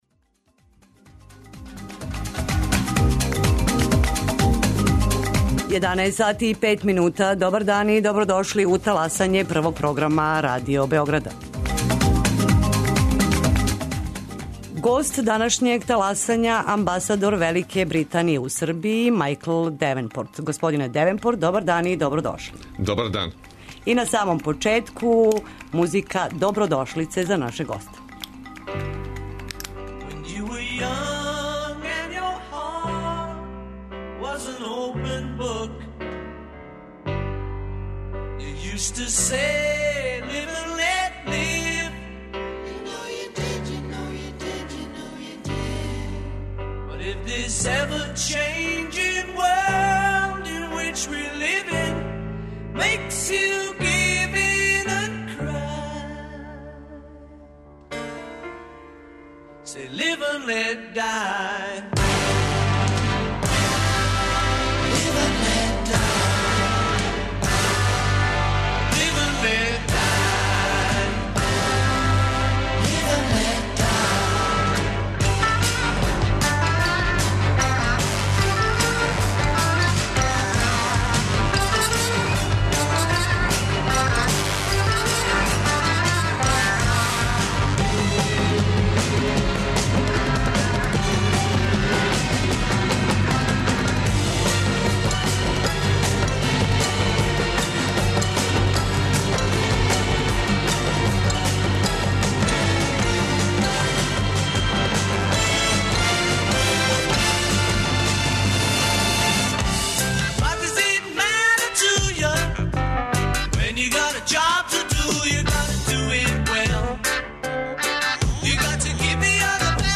Гост емисије је амбасадор Велике Британије у Србији Мајкл Девенпорт. Разговарамо о политичкој, економској и културној сарадњи Србије и Велике Британије, али и о отвореним питањима између две земље.